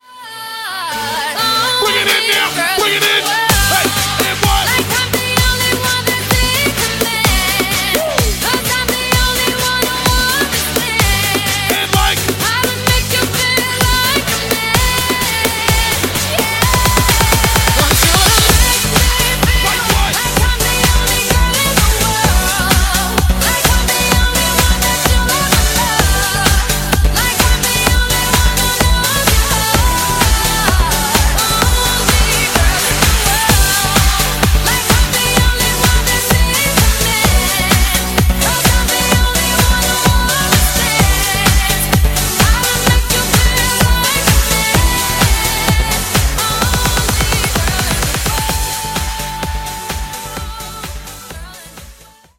Dj Intro Outro – Get Yours Now & Add To Cart
BPM: 128 Time